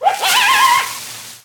foxpanic.ogg